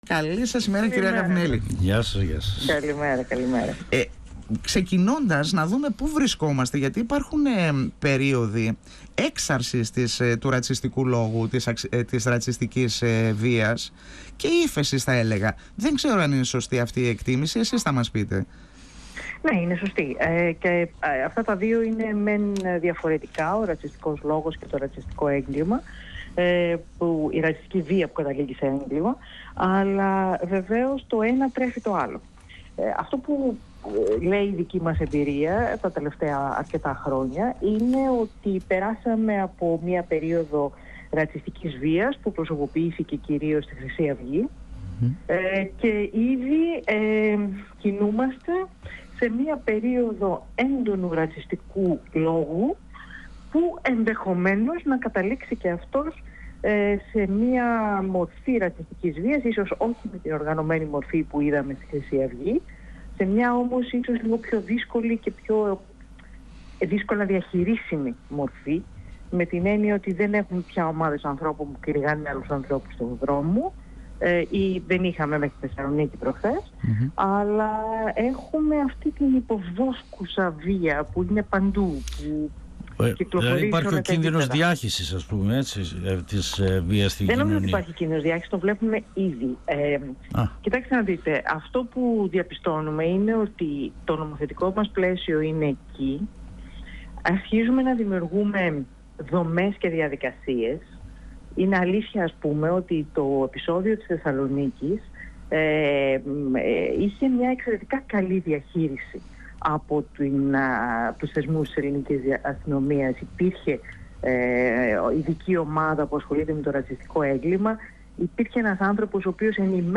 Στη Παγκόσμια Ημέρα κατά του Ρατσισμού αναφέρθηκε η καθηγήτρια της Νομικής Σχολής του Ε.Κ.Π.Α., Πρόεδρος της Εθνικής Επιτροπής για τα Δικαιώματα του Ανθρώπου Μαρία Γαβουνέλη μιλώντας στην εκπομπή «Εδώ και Τώρα» του 102FM της ΕΡΤ3.
Γαβουνέλη υπογραμμίζοντας τον αγώνα που απομένει ακόμη να γίνει για να εξαλειφθούν οι διακρίσεις και να αντιμετωπιστούν ο ρατσισμός και η ξενοφοβία. 102FM Εδω και Τωρα Συνεντεύξεις ΕΡΤ3